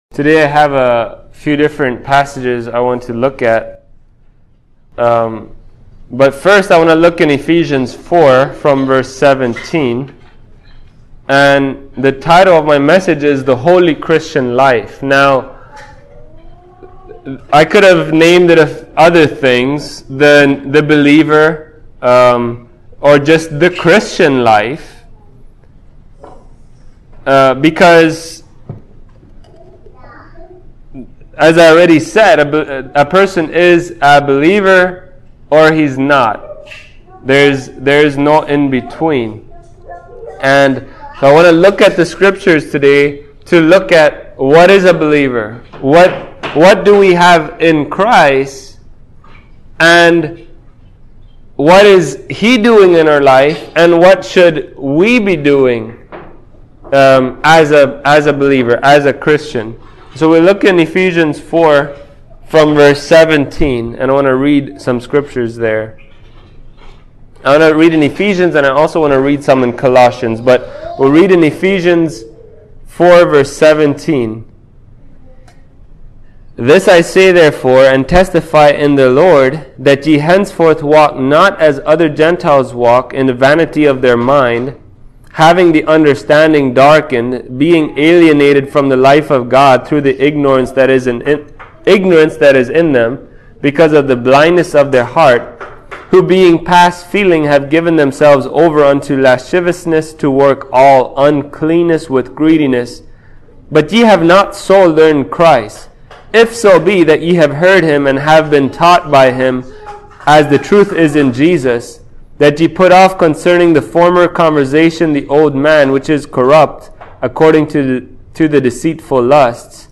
Ephesians 4:17-32 Service Type: Sunday Morning How is a Believer in Jesus Christ to live?